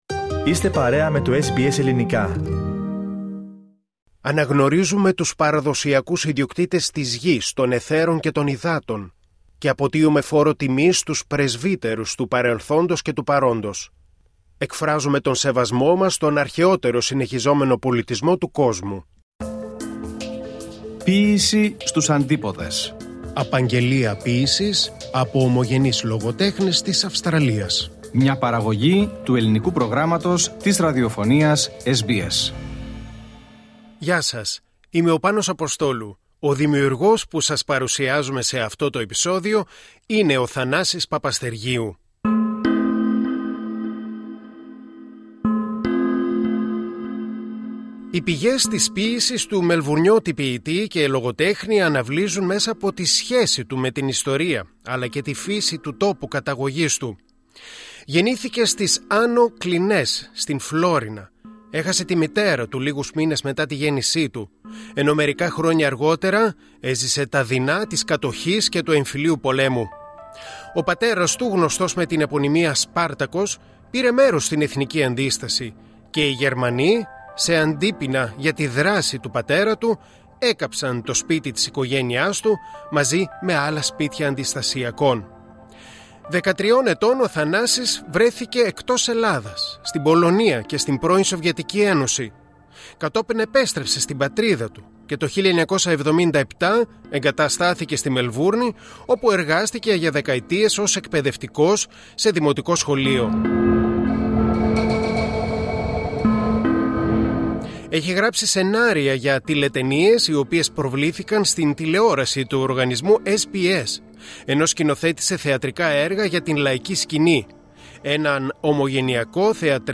επισκέφτηκε τα στούντιο του οργανισμού στη Μελβούρνη για την ηχογράφηση των απαγγελιών του